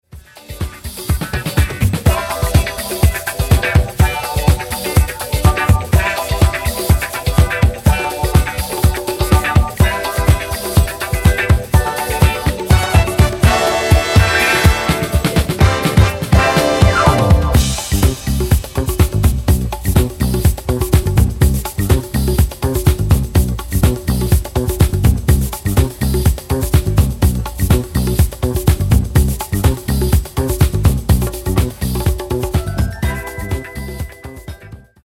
Genere:   Disco|Soul | Funky